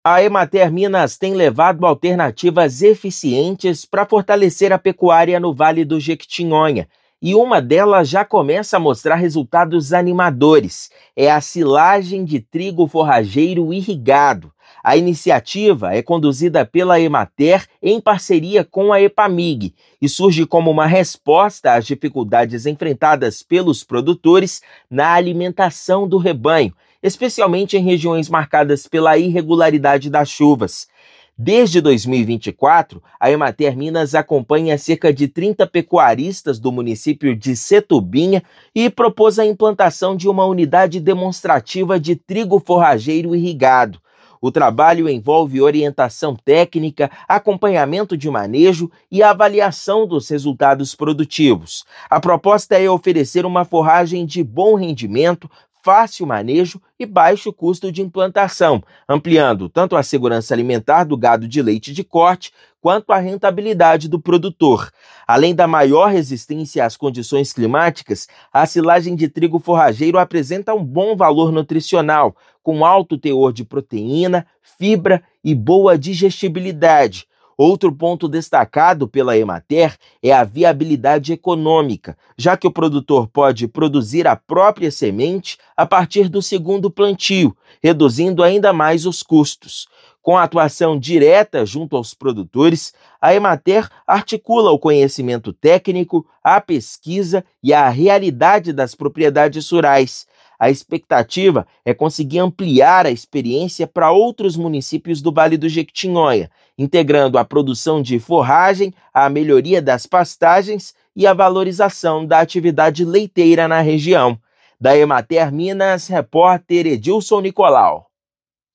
A iniciativa surge como alternativa eficiente na alimentação do rebanho. Ouça matéria de rádio.